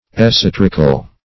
Esoterical \Es`o*ter"ic*al\, a.